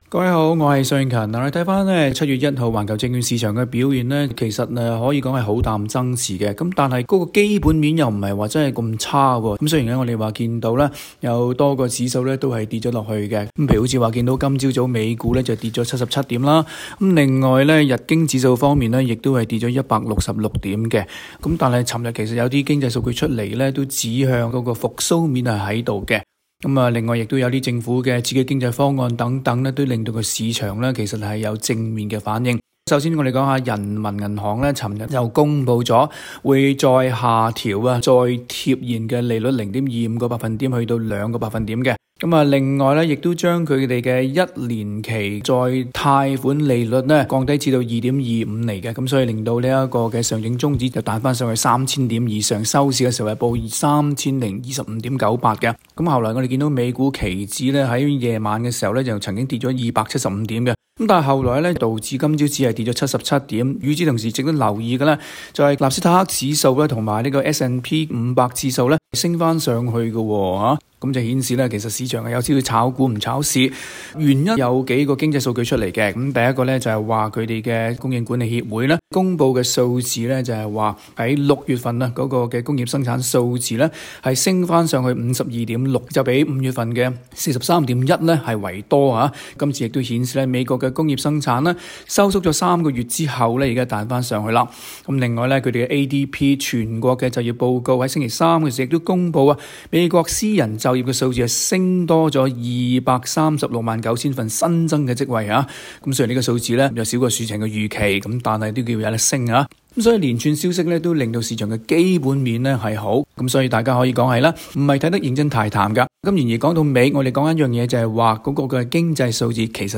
（詳情請大家收聽今期的訪問。）